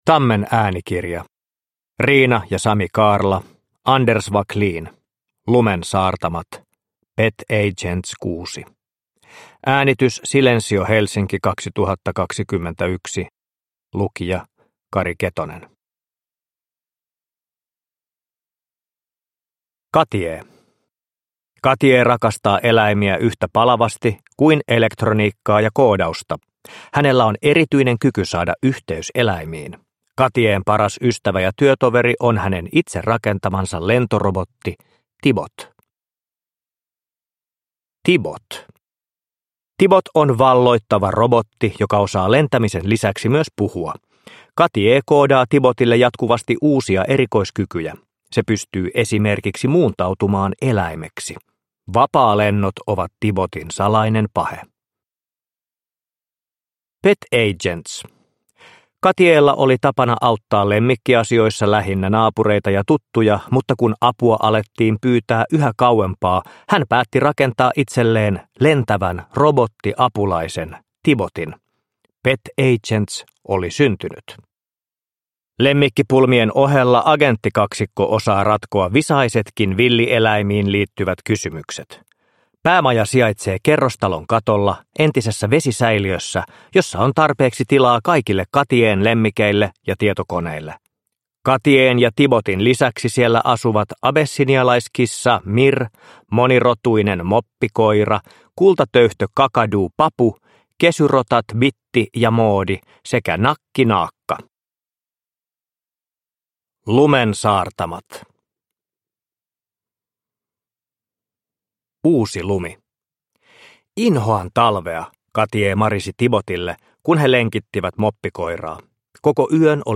Lumen saartamat. Pet Agents 6 – Ljudbok – Laddas ner
Uppläsare: Kari Ketonen